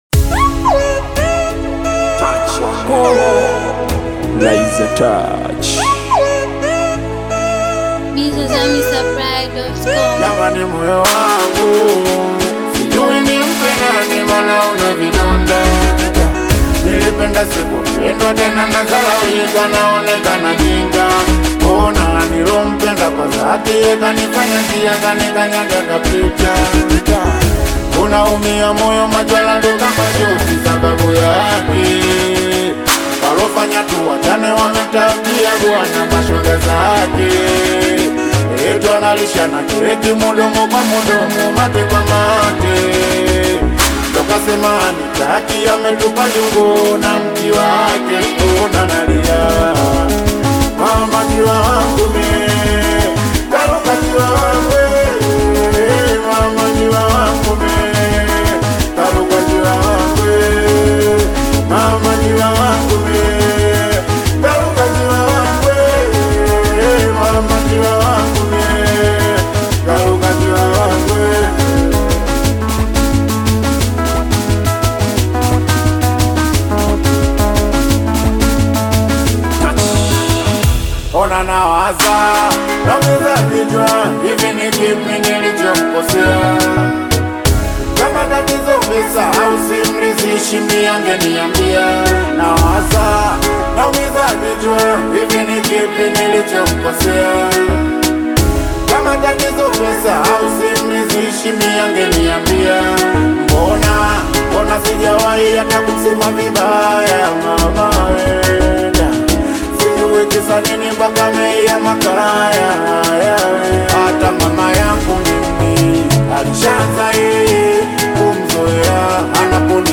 smooth and melodic new single
Singeli